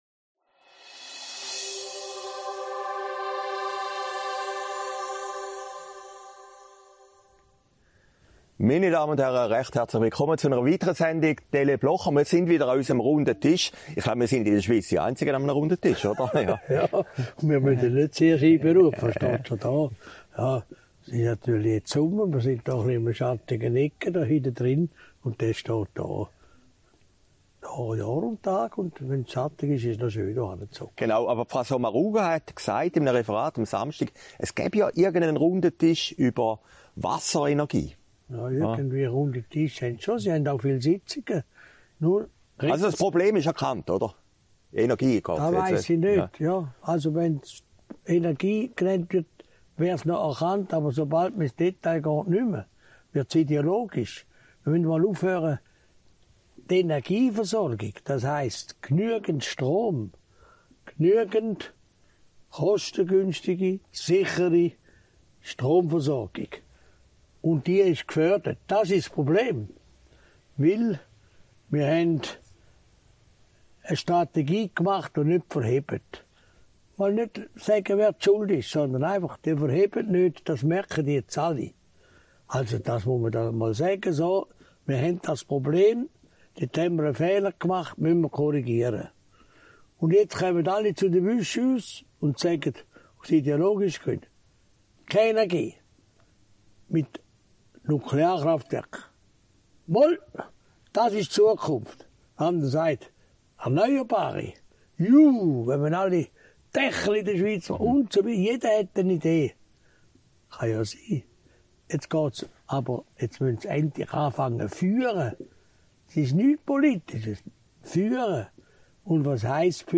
Sendung vom 11. August 2022, aufgezeichnet in Herrliberg